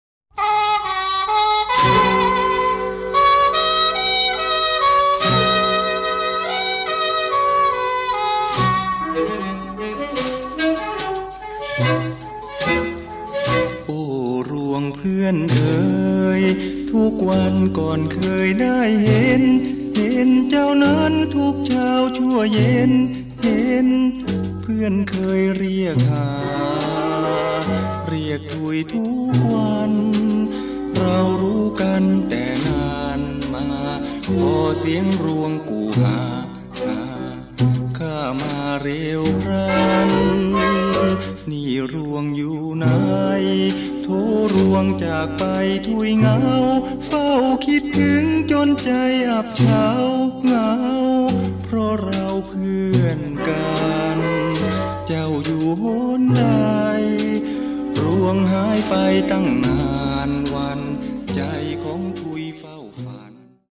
USB MP3 เพลงต้นฉบับเดิม